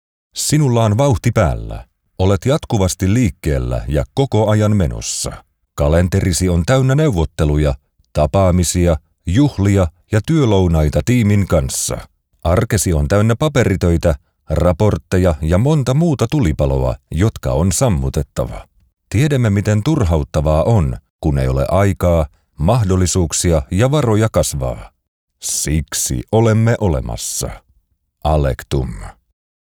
Finnish Voice Talent